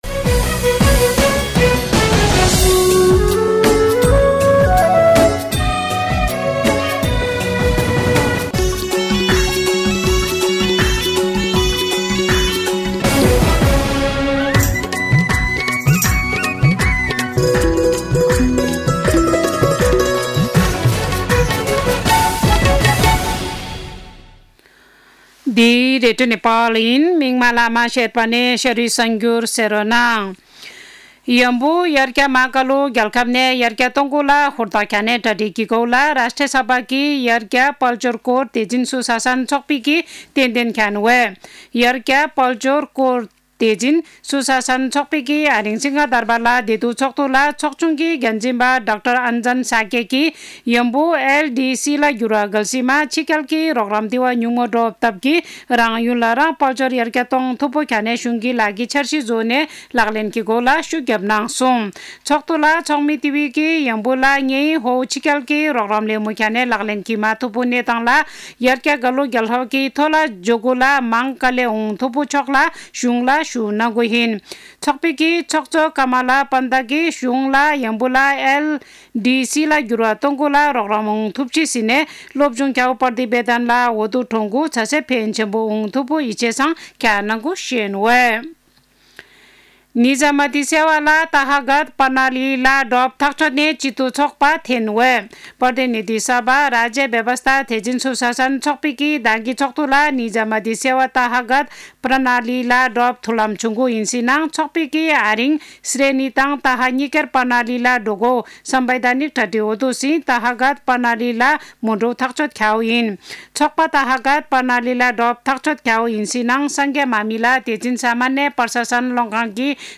शेर्पा भाषाको समाचार : ३ फागुन , २०८१
Sherpa-News.mp3